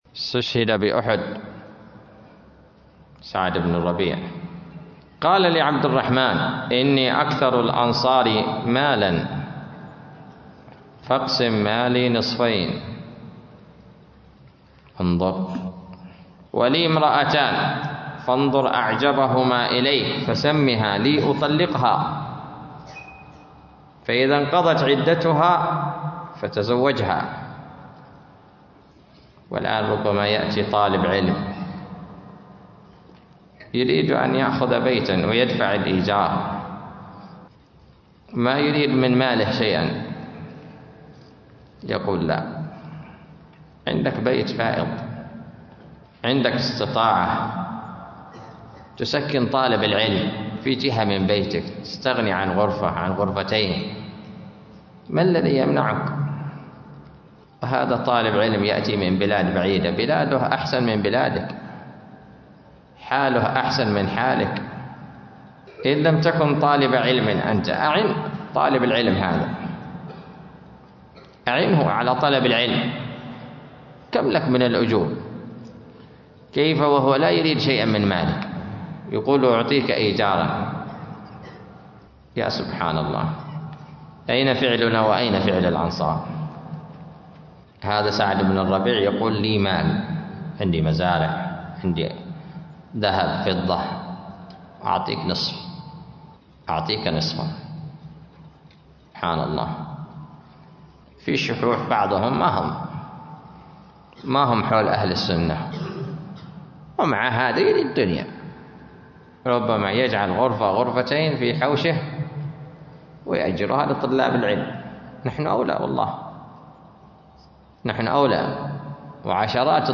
📝 سجلت من درس صحيح البخاري بعد عصر الثلاثاء بتاريخ 9 من شهر جمادى الآخرة 1446هـ
🏚 ألقيت بمسجد الريان بالرييدة ببور/ سيئون حضرموت اليمن